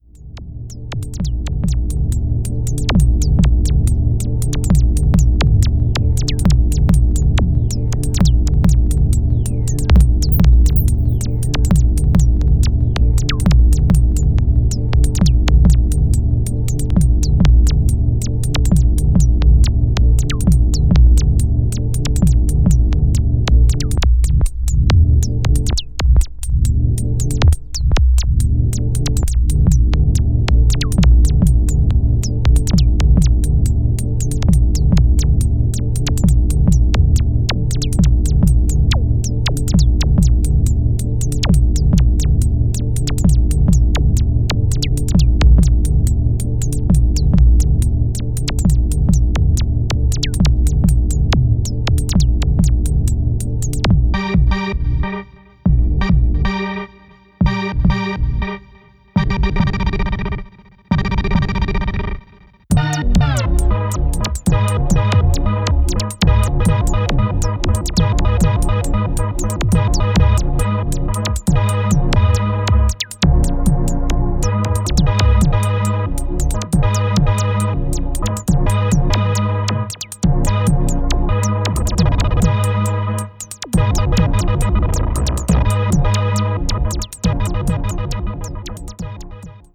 keeping a foot between club and experimental material.